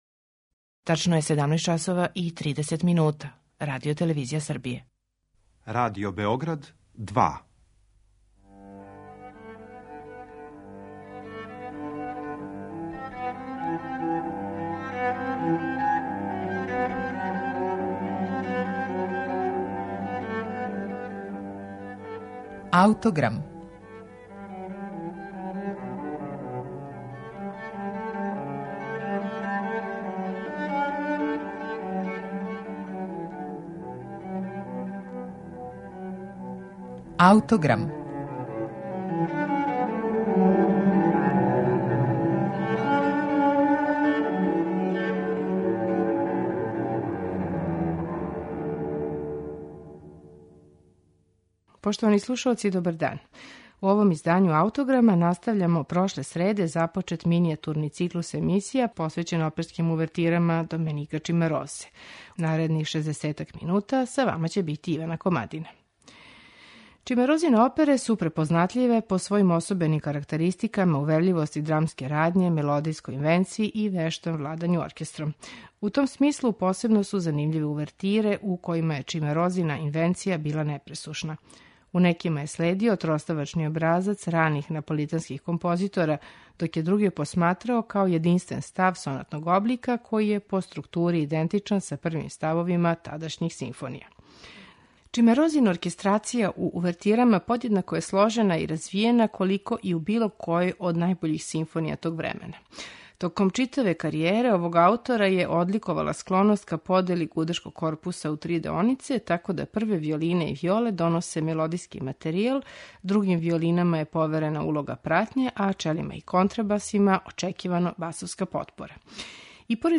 Чимарозине оперске увертире
Данашњим Аутограмом зокружујемо минијатурни циклус посвећен Чимарозиним оперским увертирама. У интерпретацији Камерног оркестра из Торонта